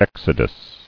[ex·o·dus]